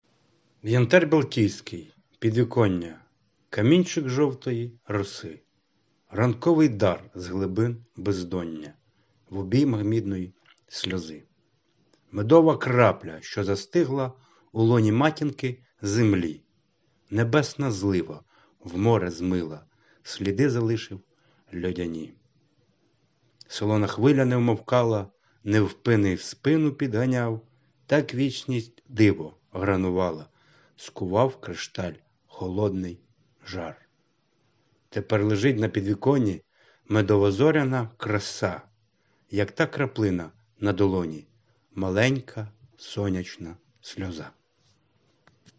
ВИД ТВОРУ: Вірш
12 Пречудовий вірш. 16 hi Ви дуже гарно декламуєте. shr 021